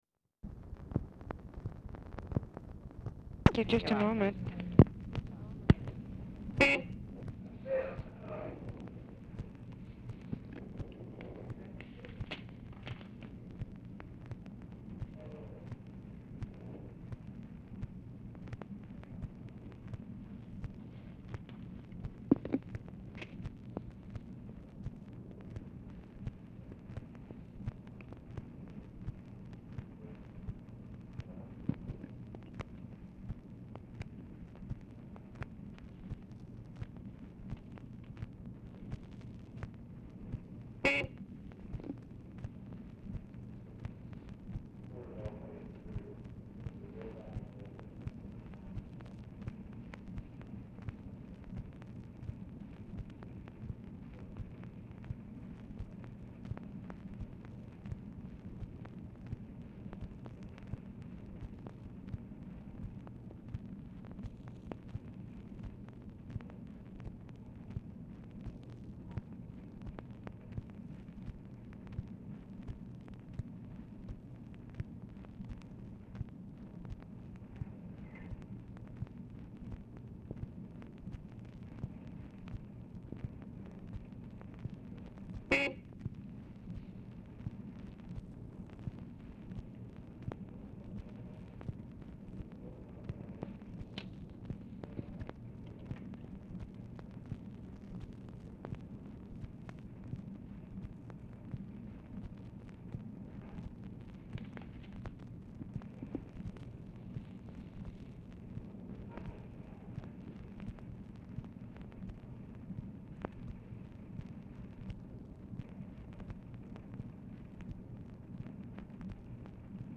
Format Dictation belt
Location Of Speaker 1 Oval Office or unknown location
Other Speaker(s) TELEPHONE OPERATOR, OFFICE SECRETARY
Specific Item Type Telephone conversation